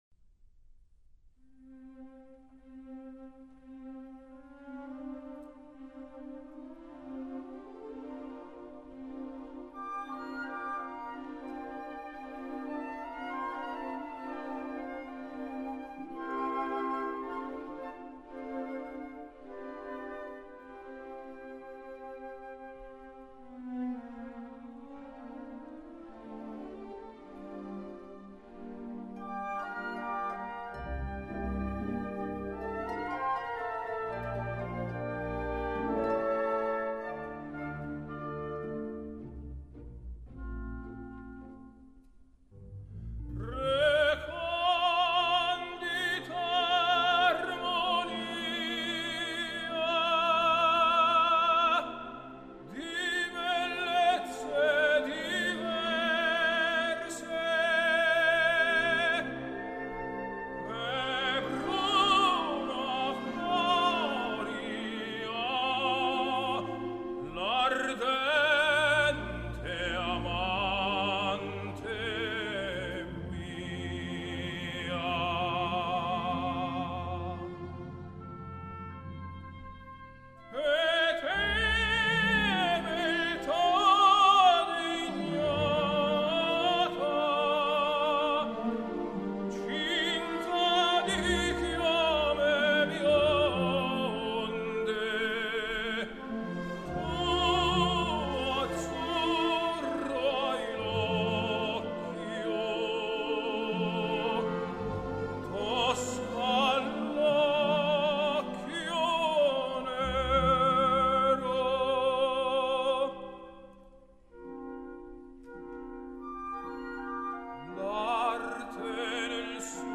类别：古典音乐
他的声音雄浑高亢，在高音处仍能保持相当柔软度却不显阴性特质